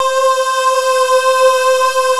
ENS 4.8 SD.wav